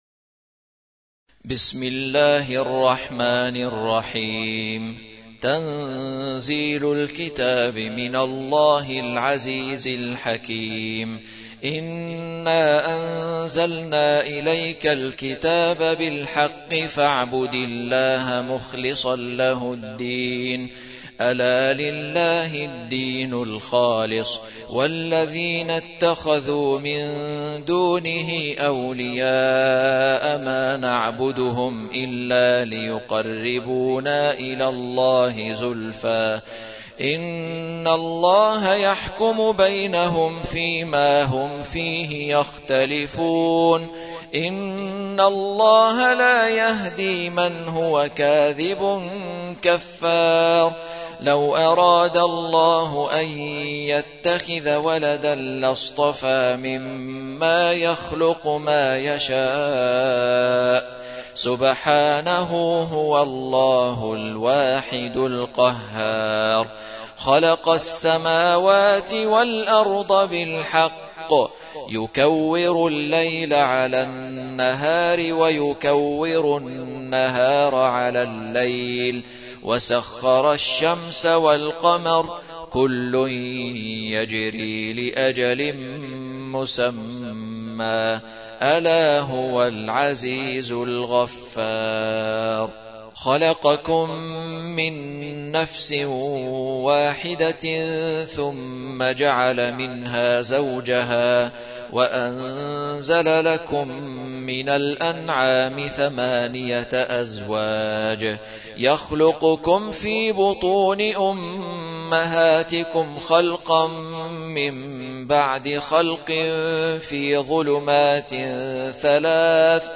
39. سورة الزمر / القارئ